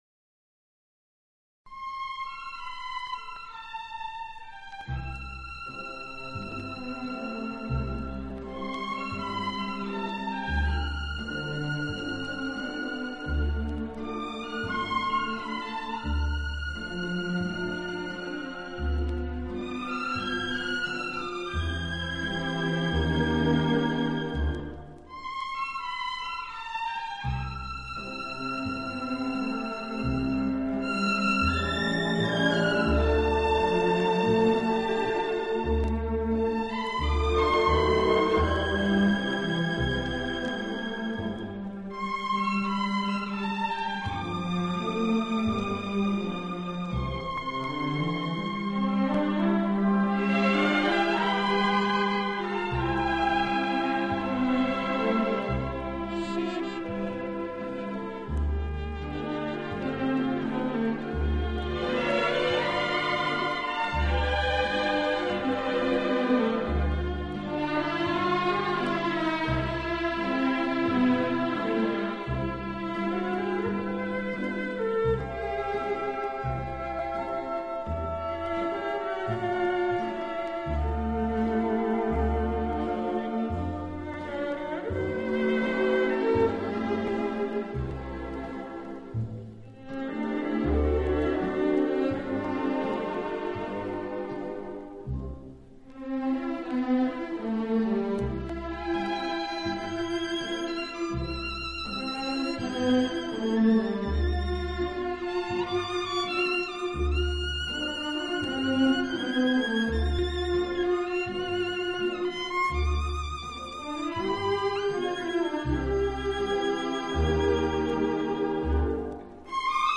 【轻音乐】
轻快、节奏鲜明突出，曲目以西方流行音乐为主。